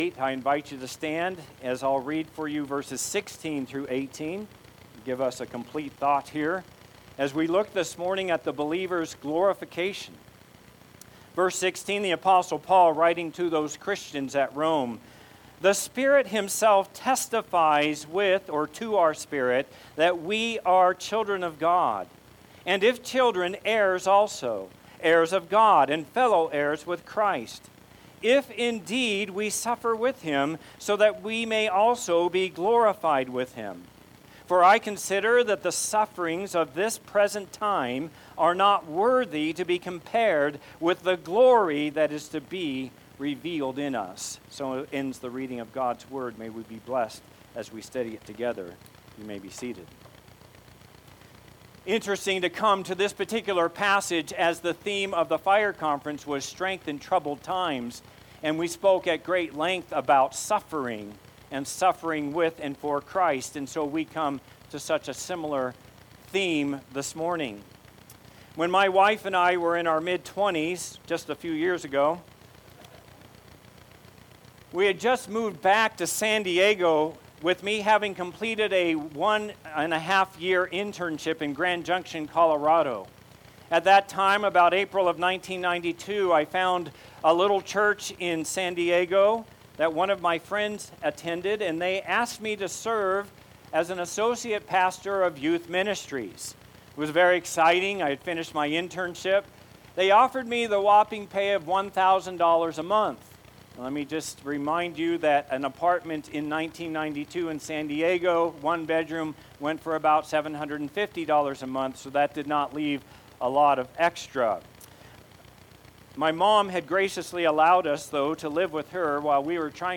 Sermons preached at Hope Community Bible Church (Hope CBC) in Rogers, AR.